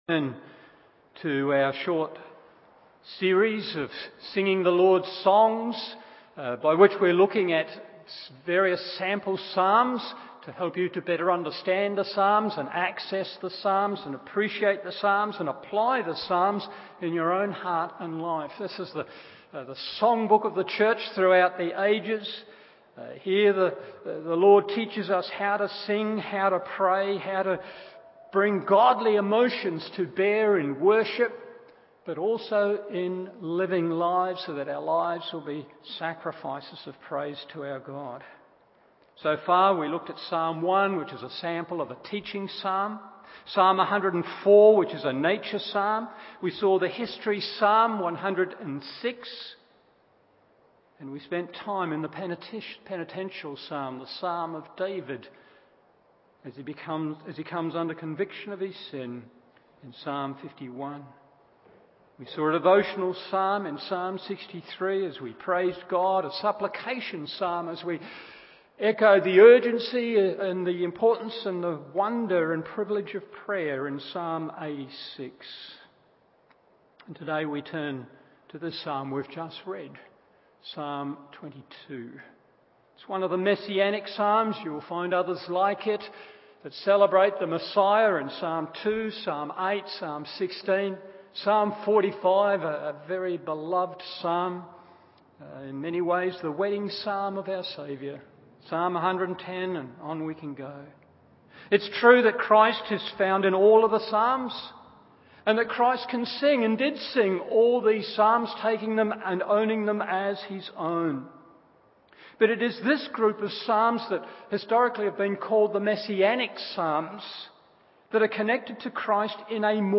Morning Service Psalm 22 1.